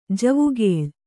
♪ javugēḷ